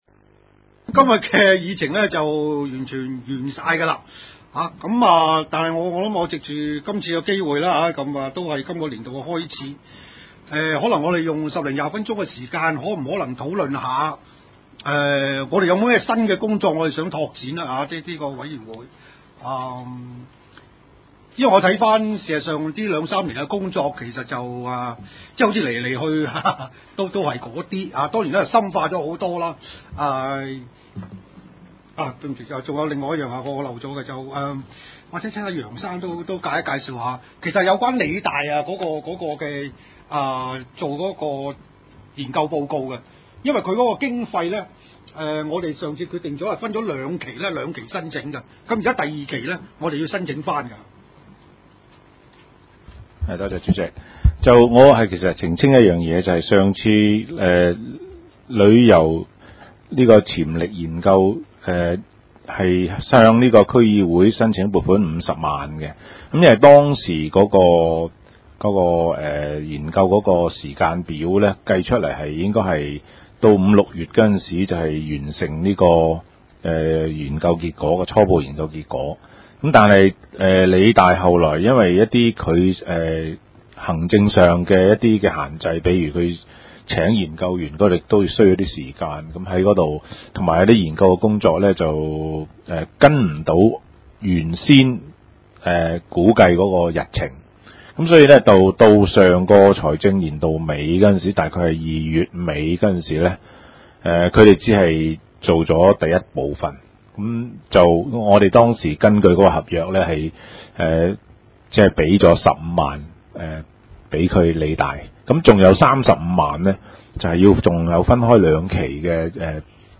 經濟發展委員會第一次會議